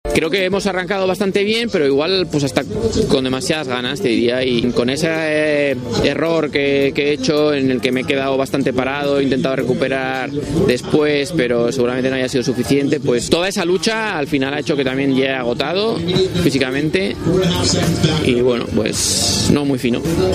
reconocía en la meta el propio esquiador guipuzcoano formato MP3 audio(0,16 MB), pero la satisfacción del segundo puesto en la supercombinada les hacía congratularse nuevamente,